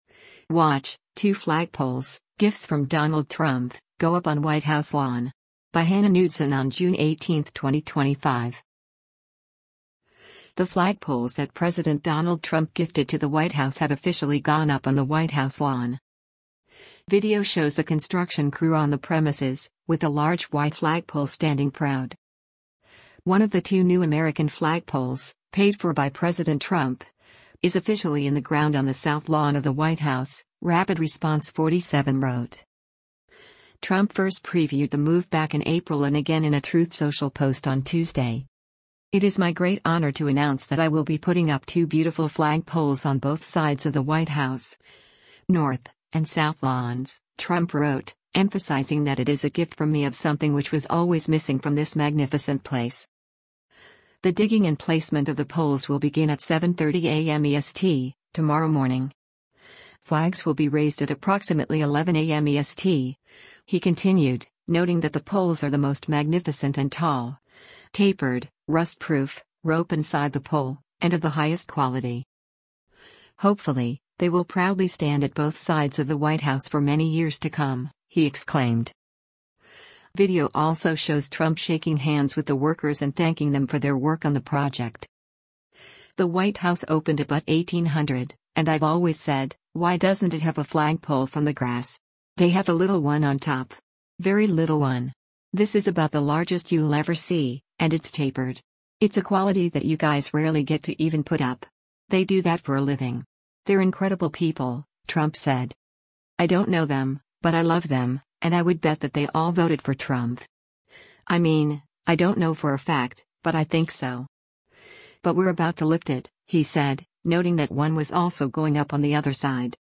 Video shows a construction crew on the premises, with a large white flagpole standing proud.
Video also shows Trump shaking hands with the workers and thanking them for their work on the project.